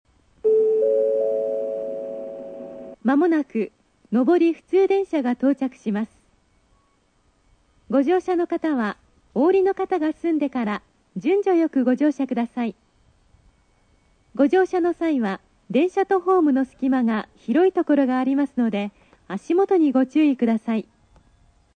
●スピーカー：TOAコラム・小
●音質：D
1番線 接近放送・下り（大牟田方面）　（106KB/21秒）
標準放送の女性版です。
見通しが悪いこともあり、比較的早くから鳴り始めます。